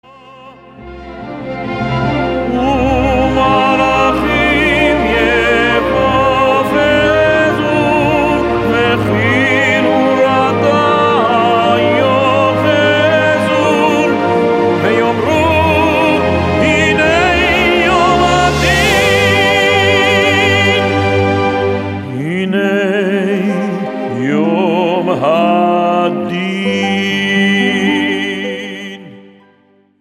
High Holy Day music
Traditional style with a modern flavor.